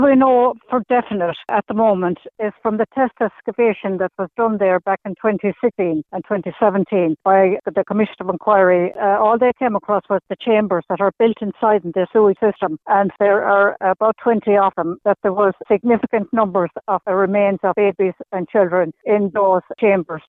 Catherine Corless, Historian, says test excavations revealed a number of babies and children’s remains were in the sewage system chambers……….